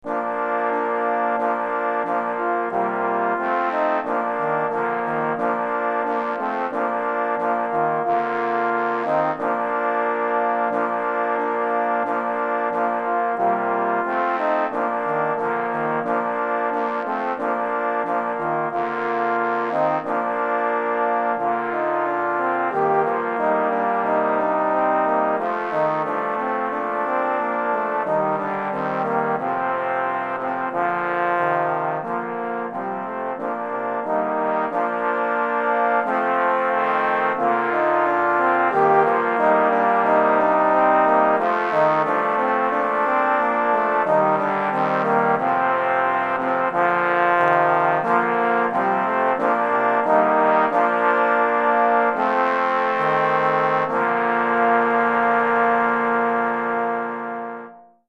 4 Trombones